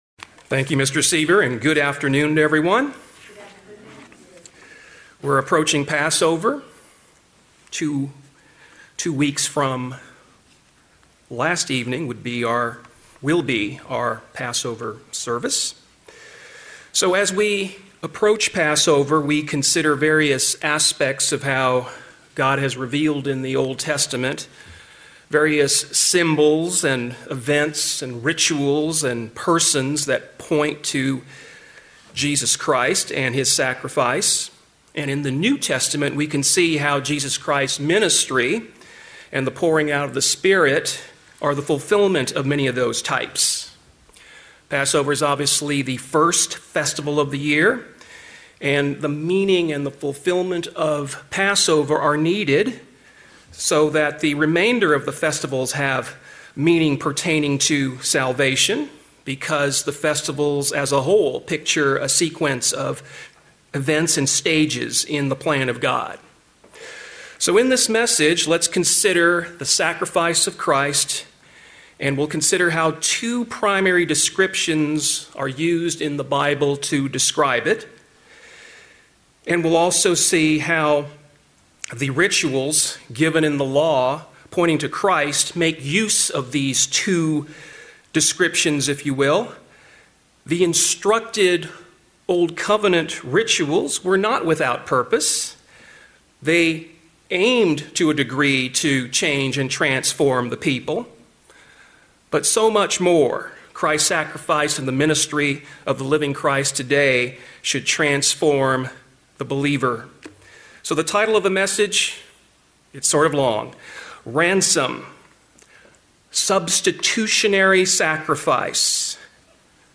Sermons
Given in San Diego, CA Redlands, CA Las Vegas, NV